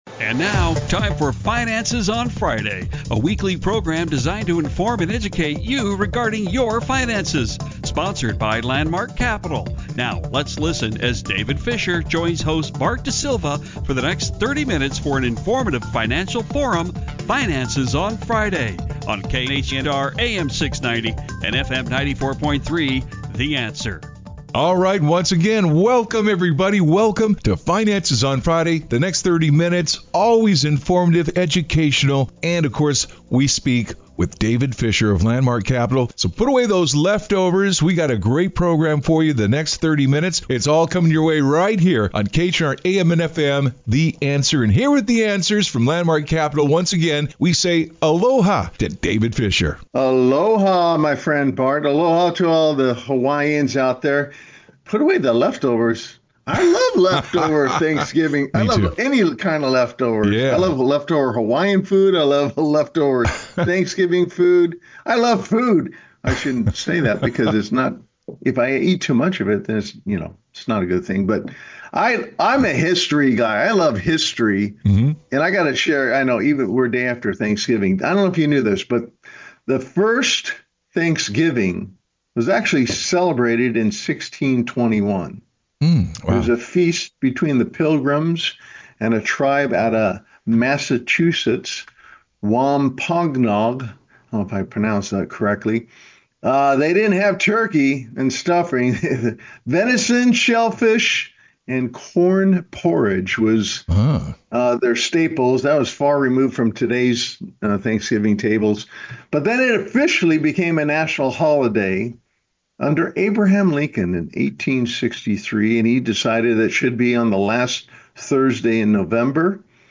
radio talk show host